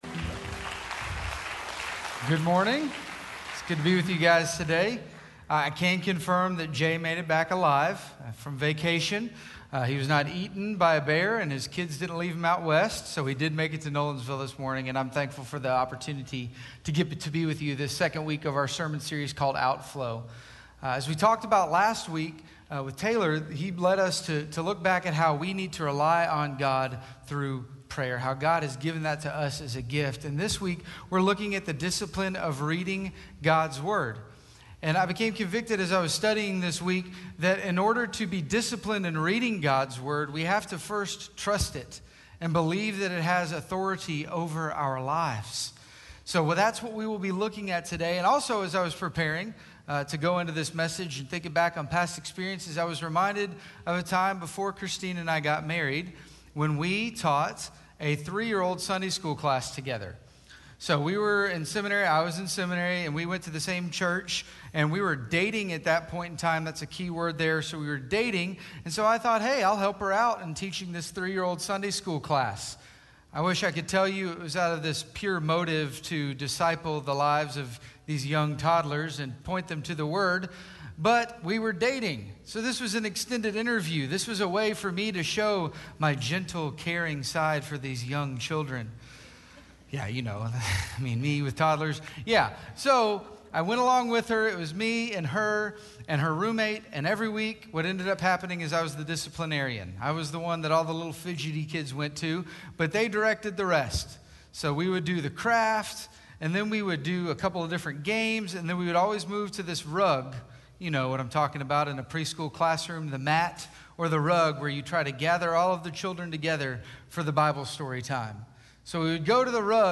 Sermons - Station Hill